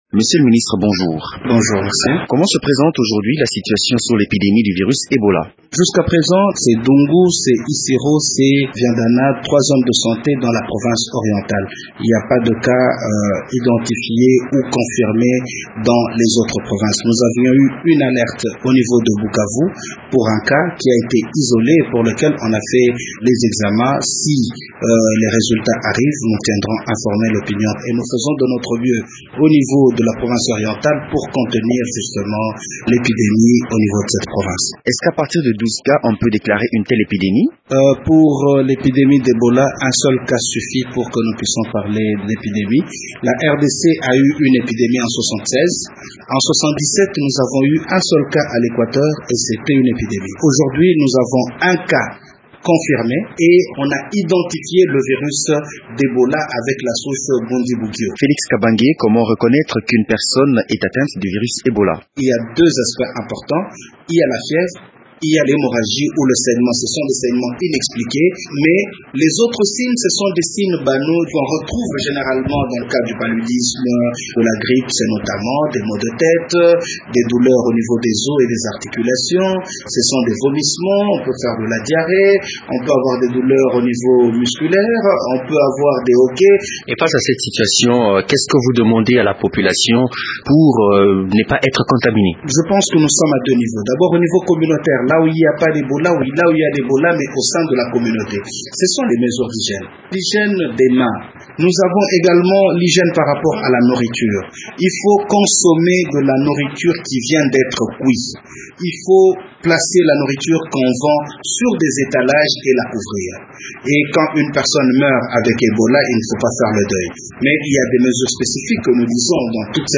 Felix Kabange Numbi revient ici sur la situation de la fièvre d’Ebola en RDC ainsi que sur le dispositif mis en place par le gouvernement pour contrer cette épidémie virale.